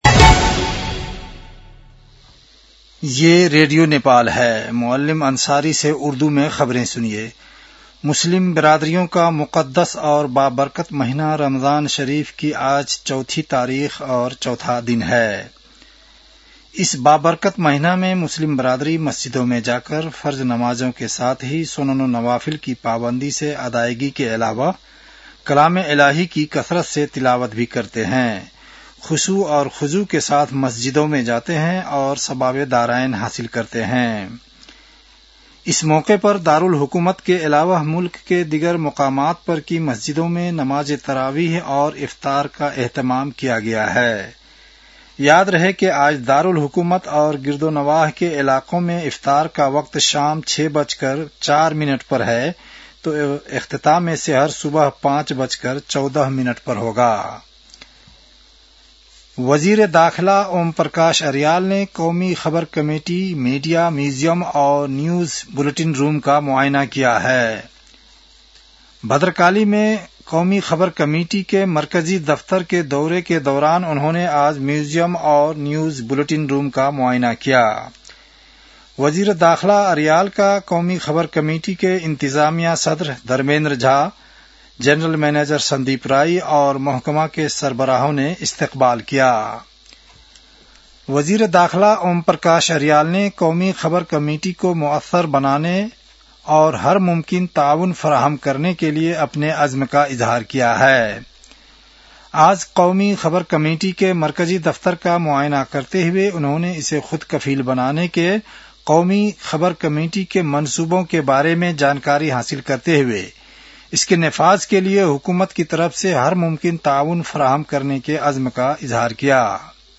उर्दु भाषामा समाचार : १० फागुन , २०८२
Urdu-news-11-10.mp3